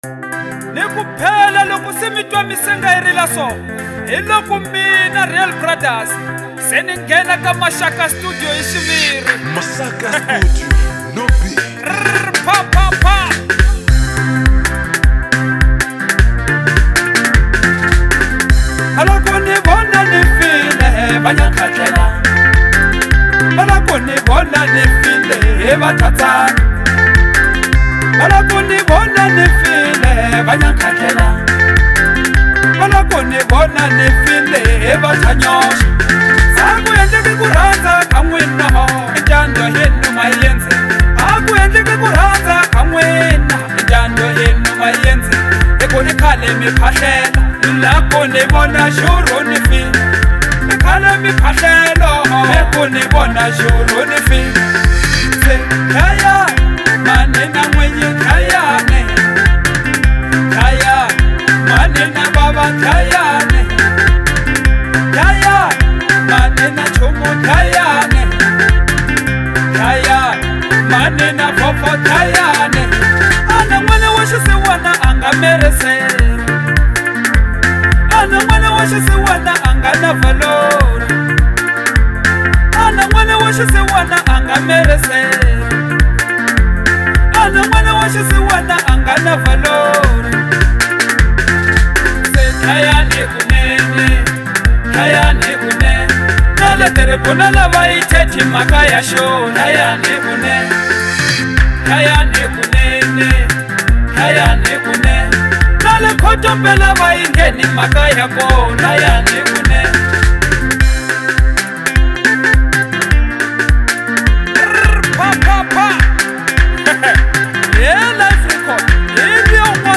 | Afro Classic